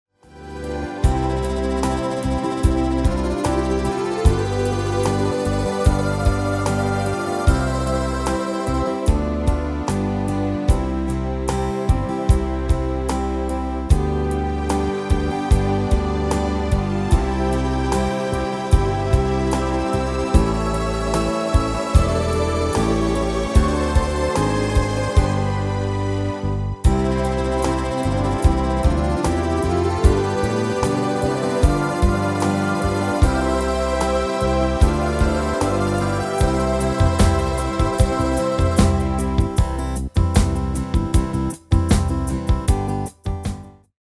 Demo/Koop midifile
Genre: Nederlandse Oldies
Toonsoort: C/Db
- Vocal harmony tracks
Demo's zijn eigen opnames van onze digitale arrangementen.